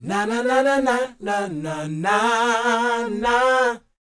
NA-NA A#B -R.wav